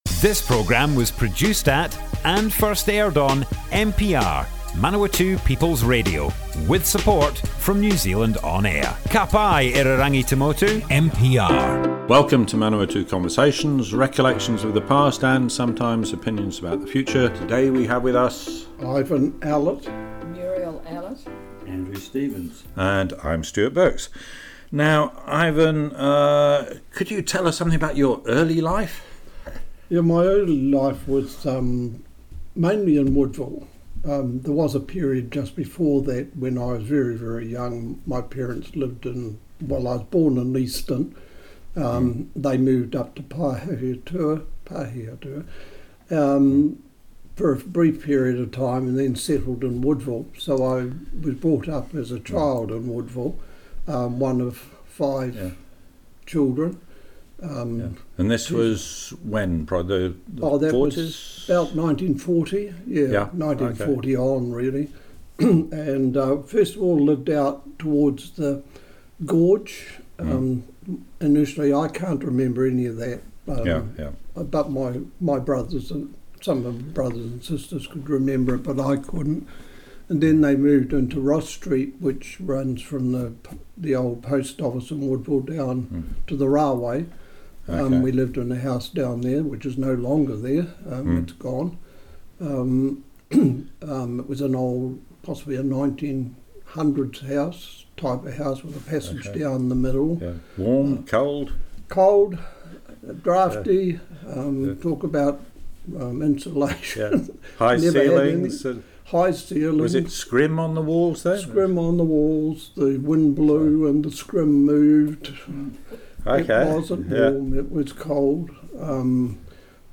Manawatu Conversations More Info → Description Broadcast on Manawatu People's Radio 31st May 2022.
oral history